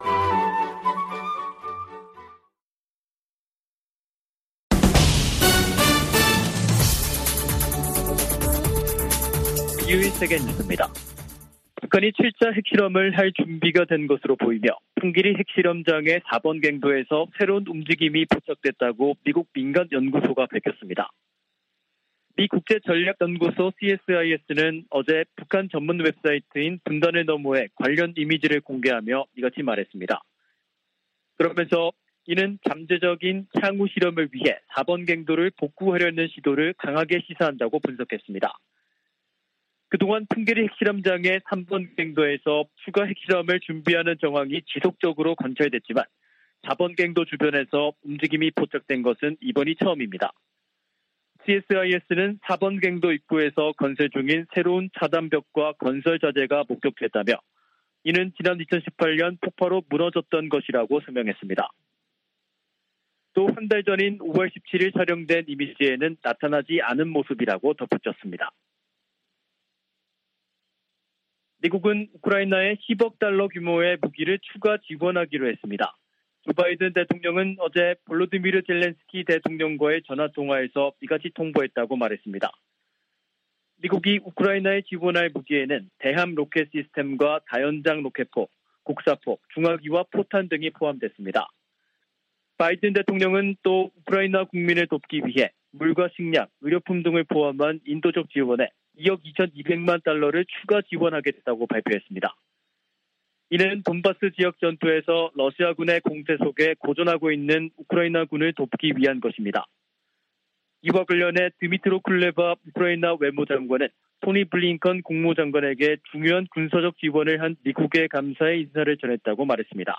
VOA 한국어 간판 뉴스 프로그램 '뉴스 투데이', 2022년 6월 16일 3부 방송입니다. 미 하원 세출위원회 국방 소위원회가 북한 관련 지출을 금지하는 내용을 담은 2023 회계연도 예산안을 승인했습니다. 북한 풍계리 핵실험장 4번 갱도에서 새로운 움직임이 포착됐다고 미국의 민간연구소가 밝혔습니다. 북한이 지난해 핵무기 개발에 6억4천200만 달러를 썼다는 추산이 나왔습니다.